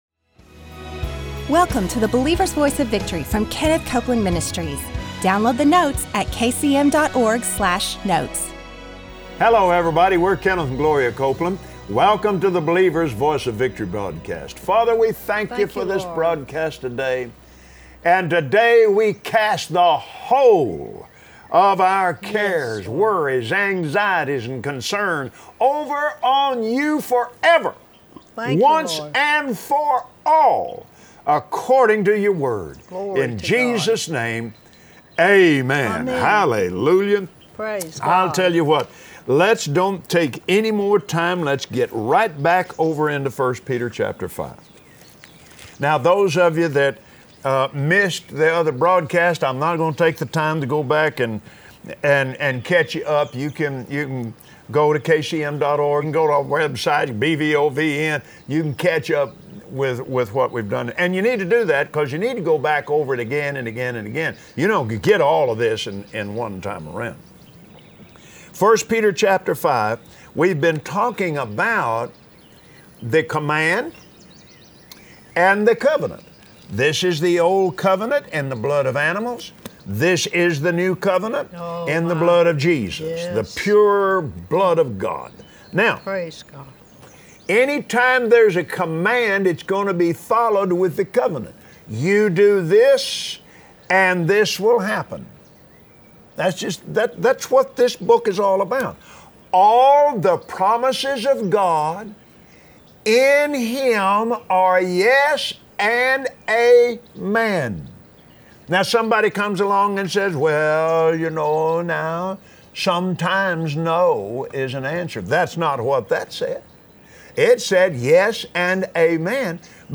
His Word brings peace so you can be free from worry. Today on the Believer’s Voice of Victory, Kenneth and Gloria Copeland teach you how to keep your heart and mind at peace through the anointing of Jesus.